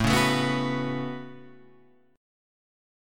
A 6th Add 9th